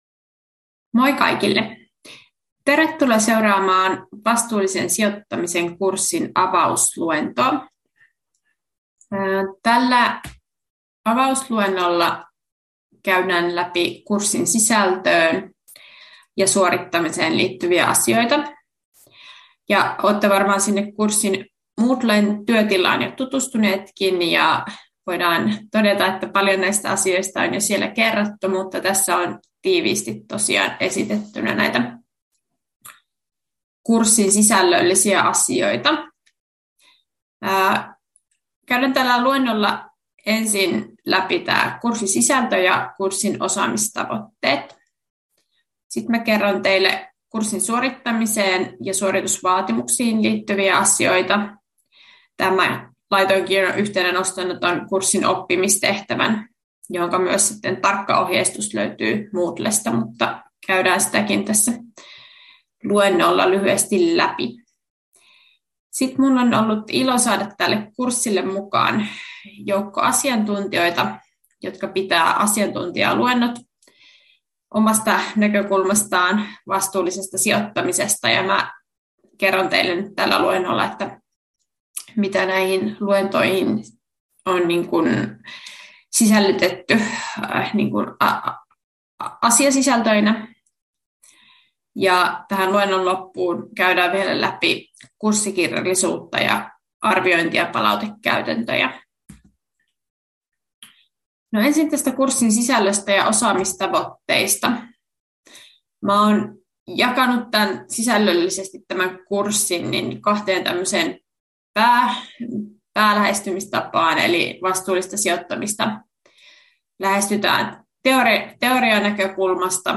Avausluento 2025 — Moniviestin